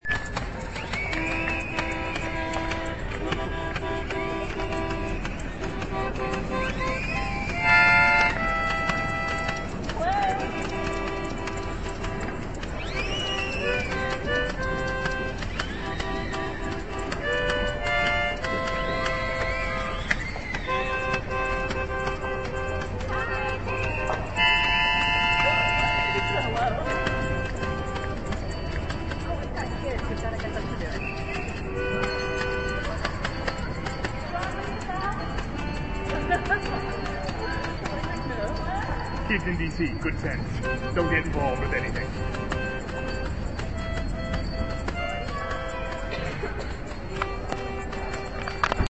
plastic bag
maracas
hula hoop and whirligig
orchestra43852.mp3